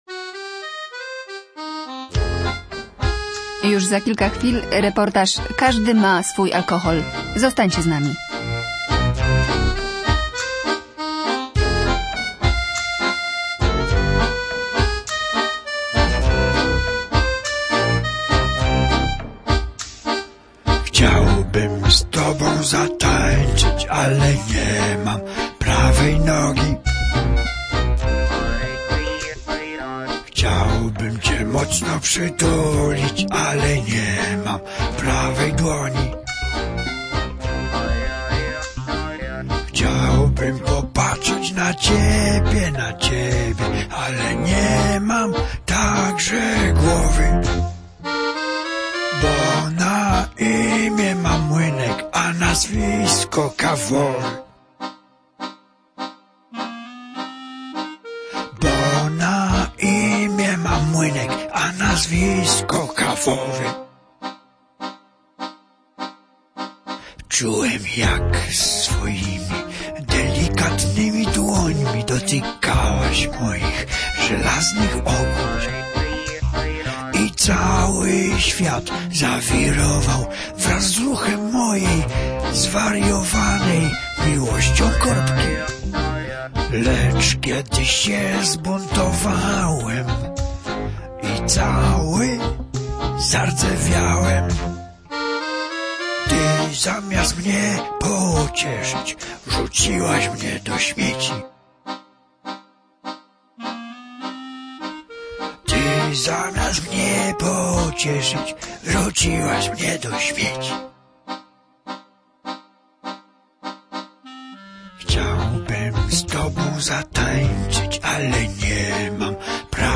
Każdy ma swój alkohol - reportaż